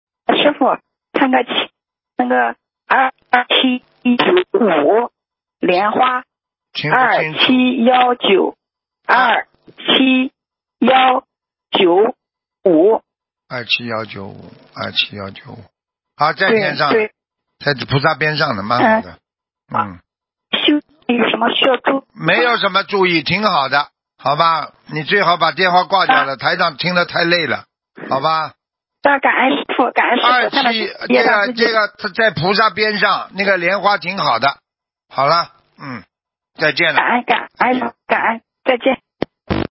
目录：2019年12月_剪辑电台节目录音_集锦